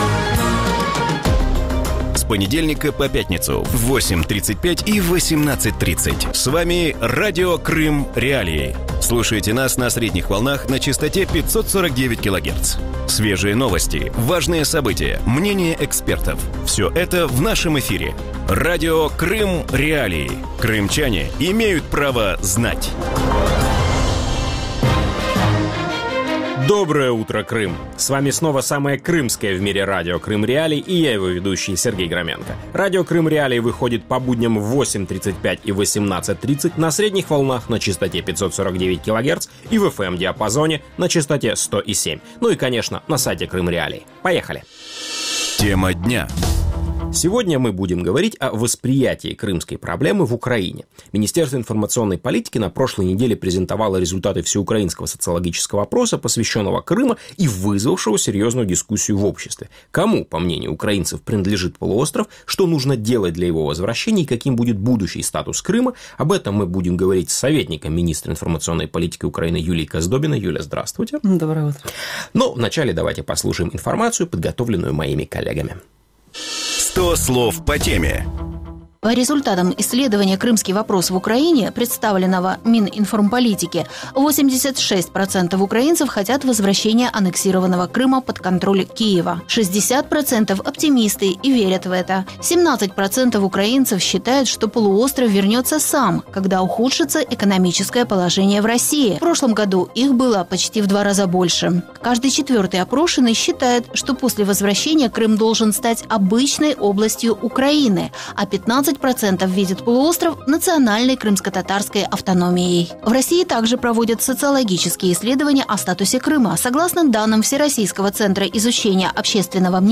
Утром в эфире Радио Крым.Реалии говорят о восприятии крымской проблемы в Украине.